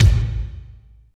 33.09 KICK.wav